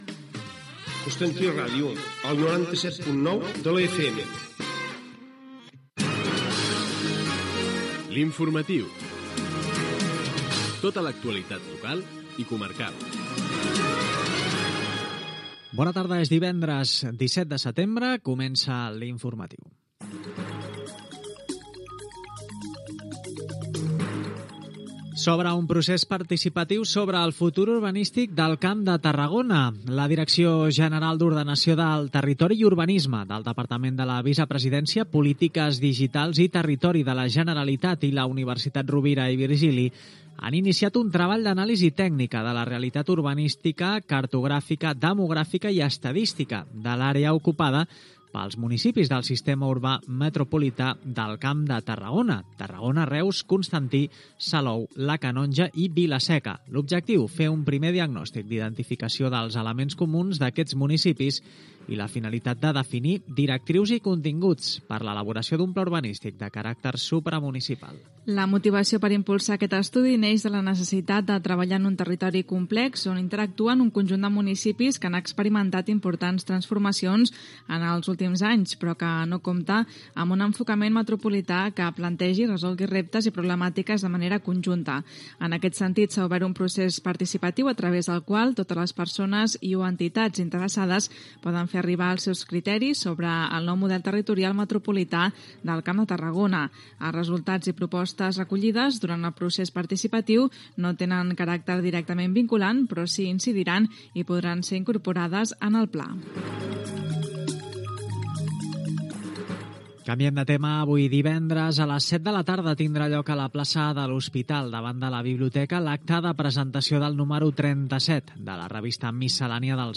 Indicatiu de l'emissora, careta del programa i sumari informatiu.
Informatiu